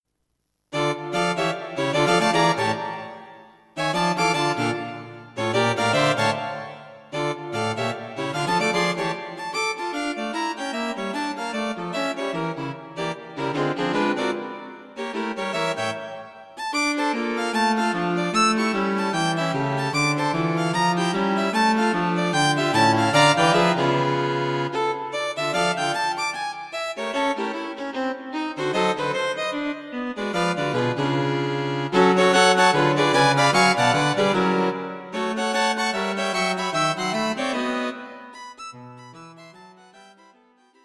String Quartet for Concert performance
An up-tempo number played with a swing.
Arranged for String Quartet.